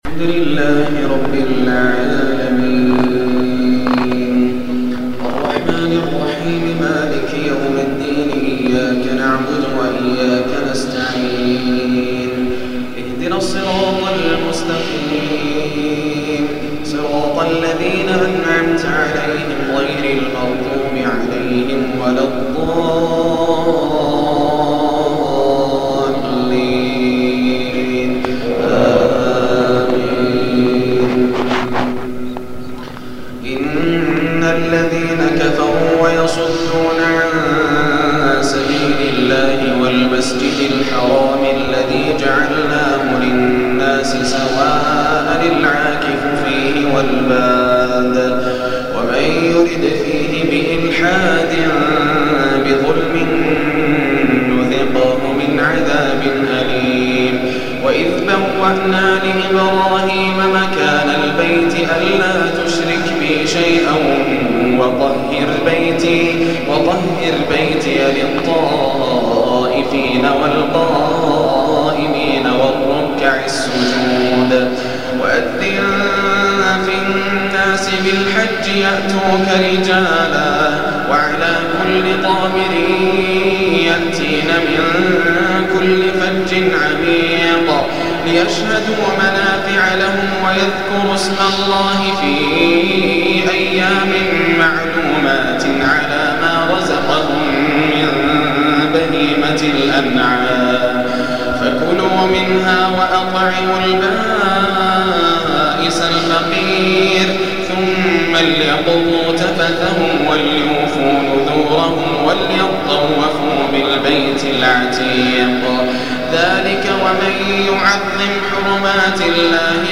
ما تيسر من سورة الحج 25 - المؤمنون 44 > رمضان 1424 هـ > التراويح - تلاوات ياسر الدوسري